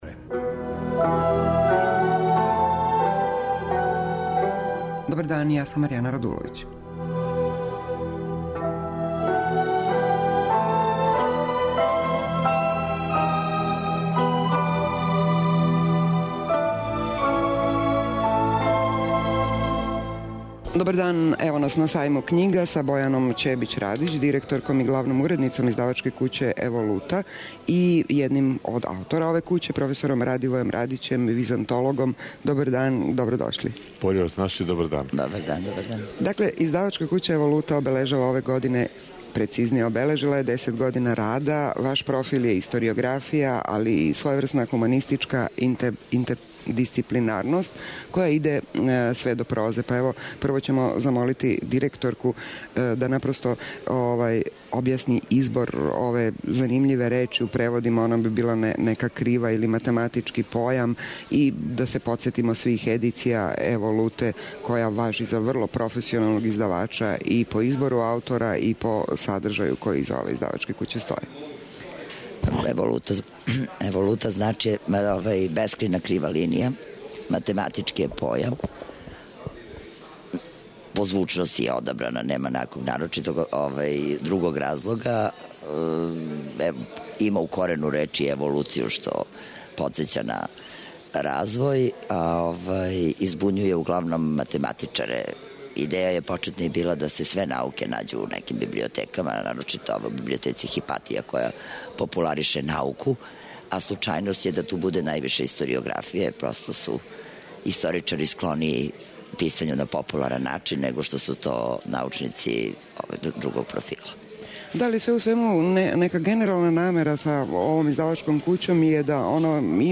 Са Међународног београдског сајма књига, из студија Радио Београда 2, разговарамо са